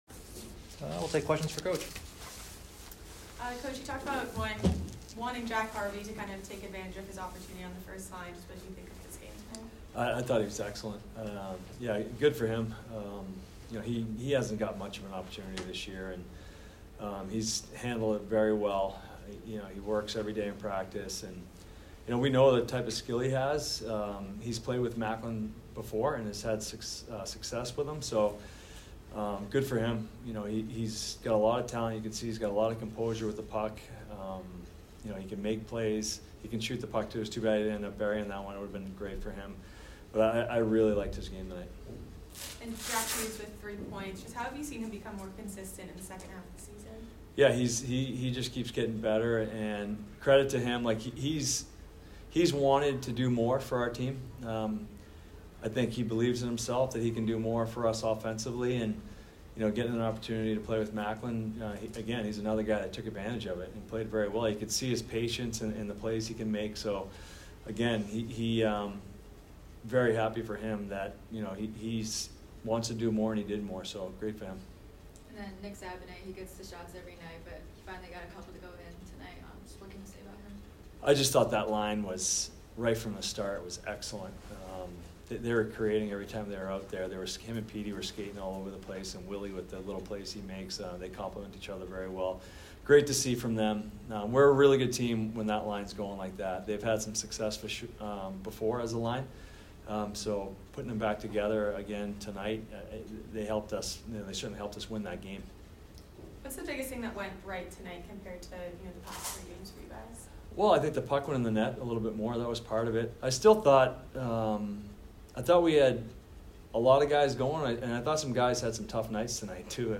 New Hampshire Postgame Interview
UNHPostgame.mp3